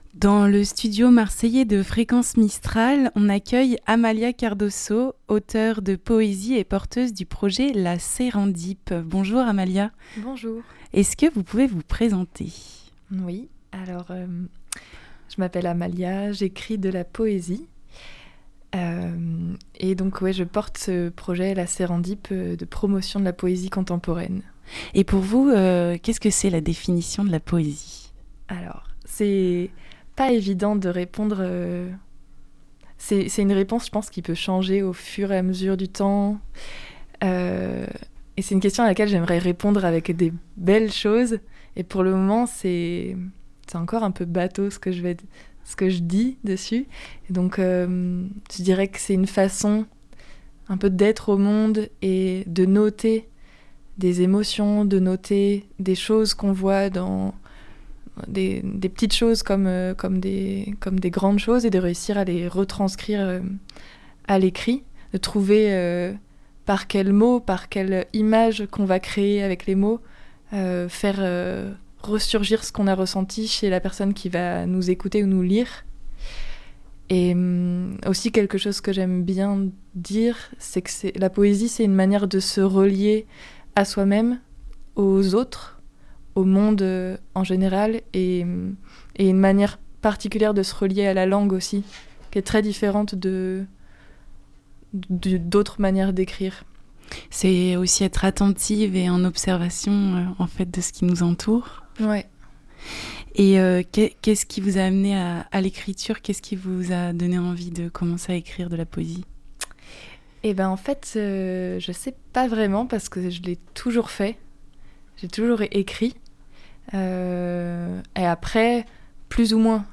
Portrait d'artiste